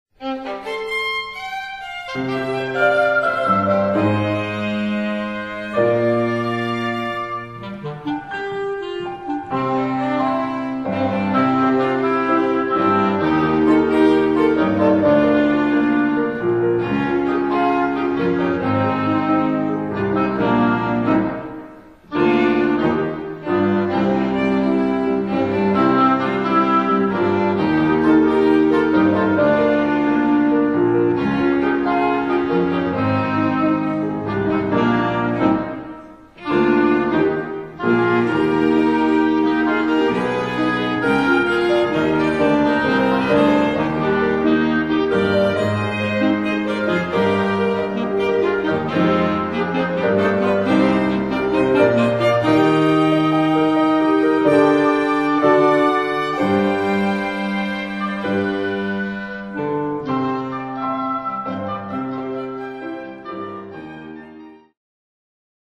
音楽ファイルは WMA 32 Kbps モノラルです。
Oboe、Clarinet、Violin、Piano